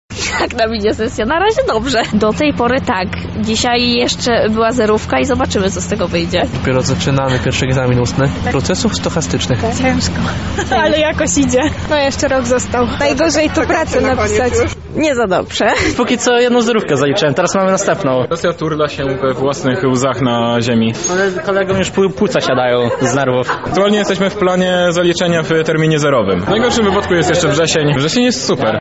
Zapytaliśmy żaków o to, jak im idą przygotowania do sesji:
Sonda -sesja